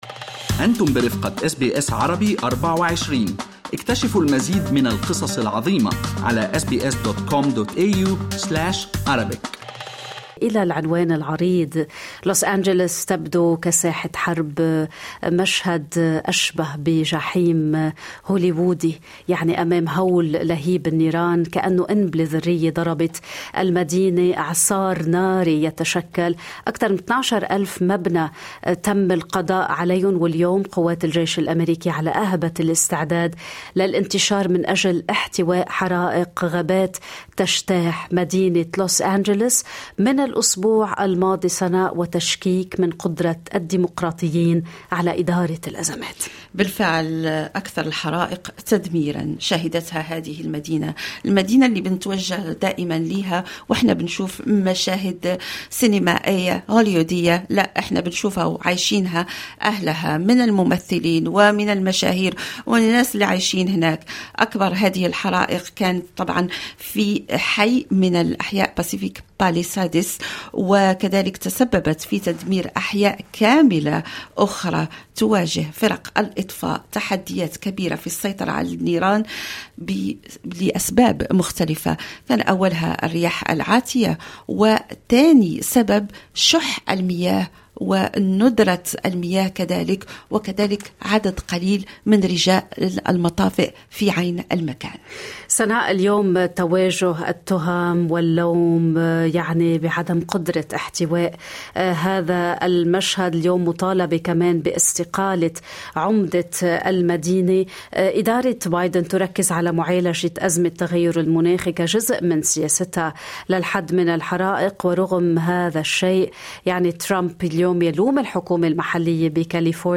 لن يتم تعويضنا إلا بمبلغ 3 مليون دولار في بيت قيمته 15 مليون دولار: سيدة عربية من لوس أنجلوس تتحدث